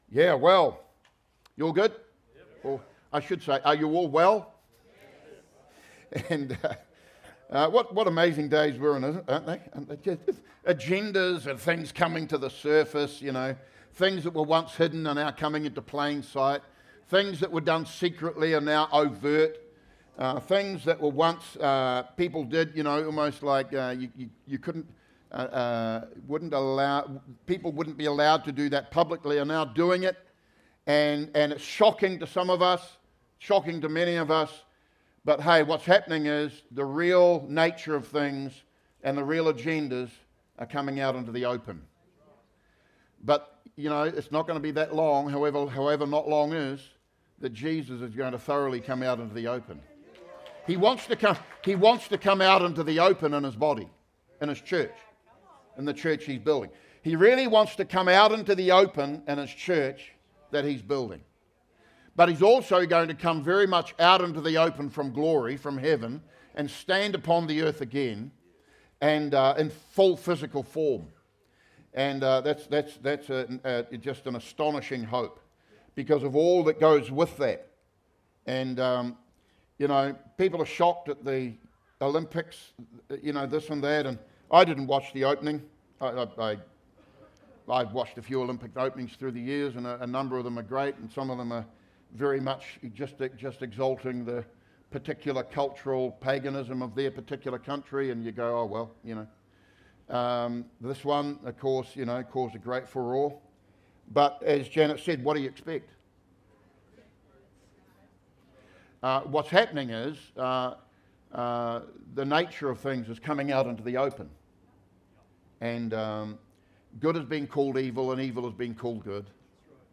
Sermons | Living Waters Christian Centre
9:30am gathering